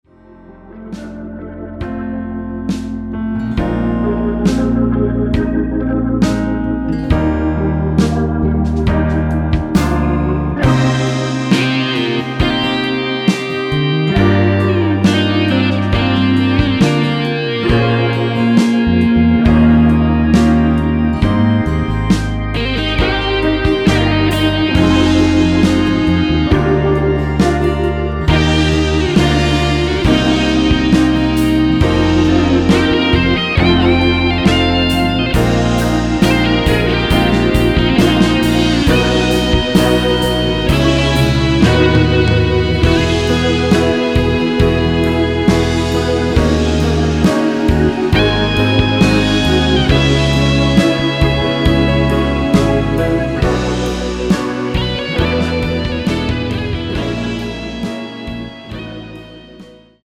1절 “우리 유일한 대화일지 몰라” 다음 두번째 “한걸음 이제 한걸음일 뿐”으로 진행되게 편곡 되었습니다.
원키 1절후 후렴으로 진행 되는 MR입니다.(본문의 가사 부분 참조)
Eb
앞부분30초, 뒷부분30초씩 편집해서 올려 드리고 있습니다.